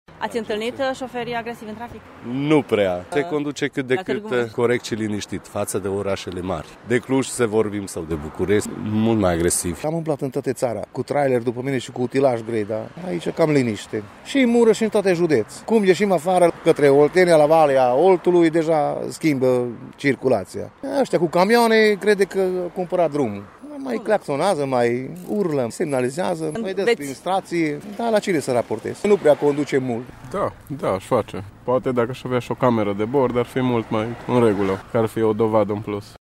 Șoferii târgumureșeni spun că cele mai multe comportamente agresive în trafic sunt întâlnite în orașele aglomerate, mai puțin în Târgu Mureș: